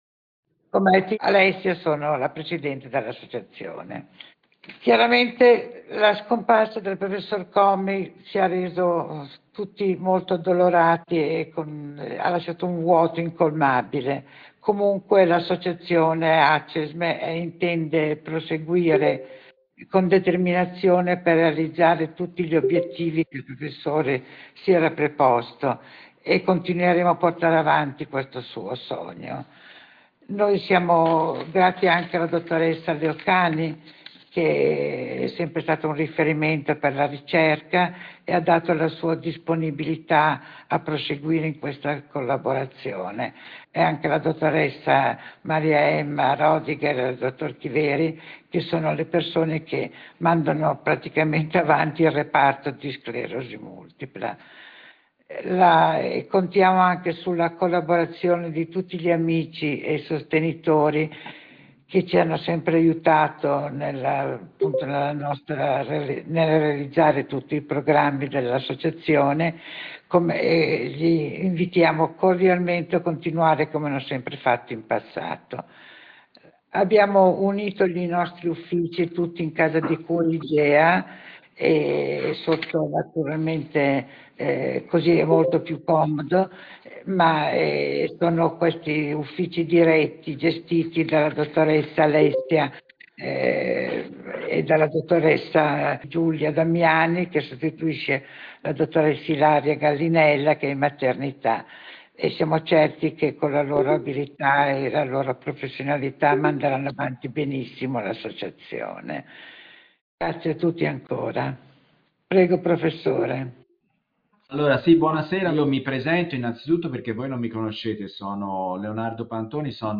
Come promesso, per quanti di voi che non sono riusciti a partecipare all’incontro online di gennaio 2025 ma anche per chi si è collegato e avesse voglia di riascoltare tutti gli interventi ecco la registrazione della teleconferenza.